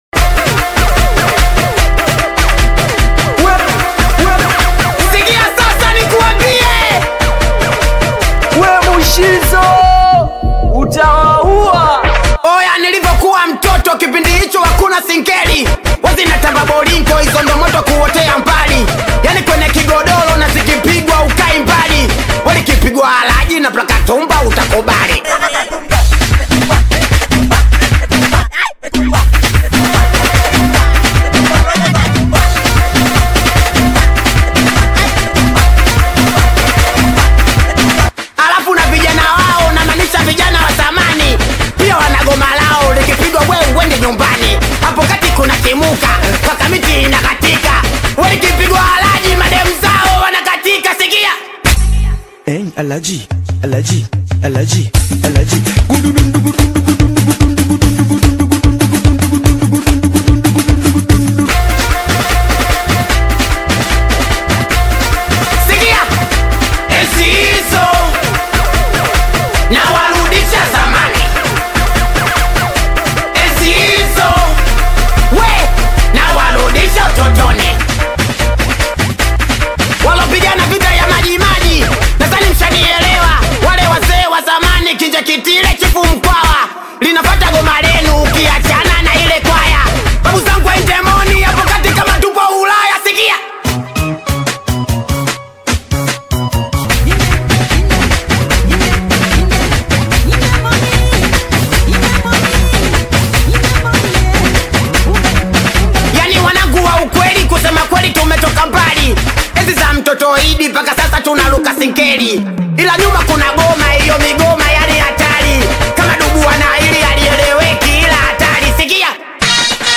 Singeli music track
Tanzanian Bongo Flava